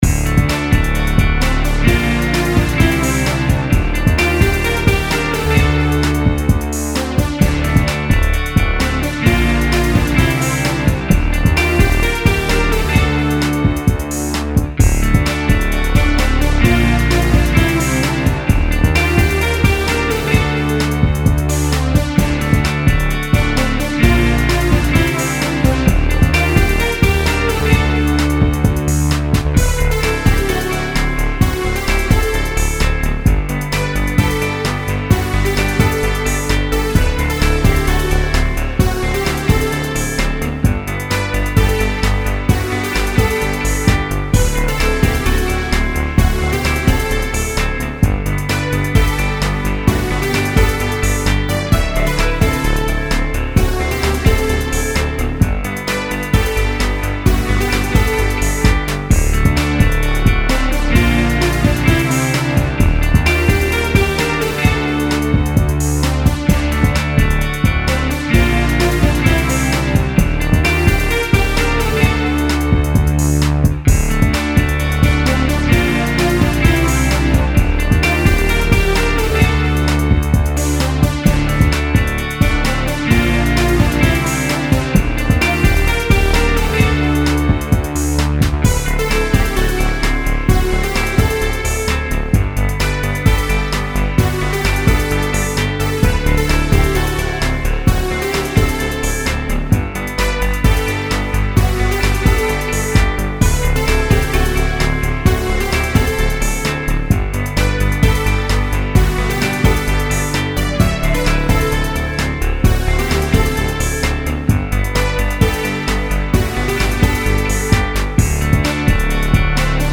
音楽ジャンル： ロック
楽曲の曲調： MIDIUM